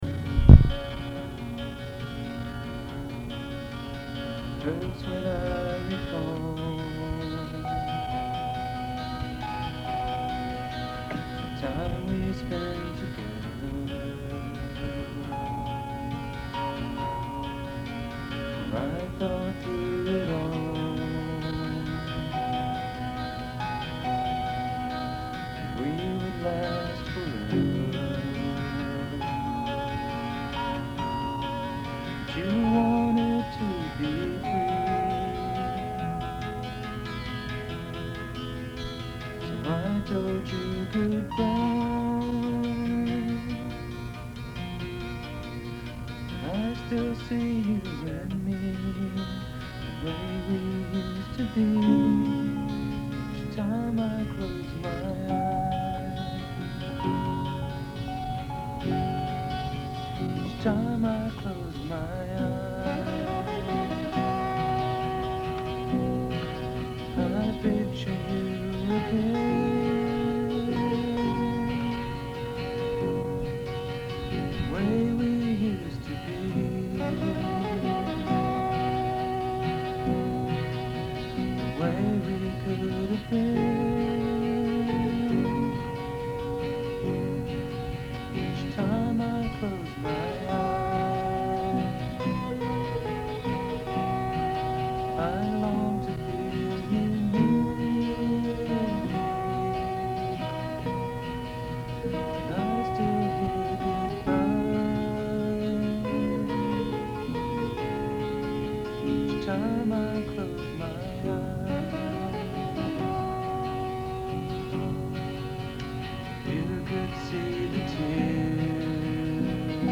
This is a rough cut from when we were trying to learn the song, but it’s the best demo I have recorded to date.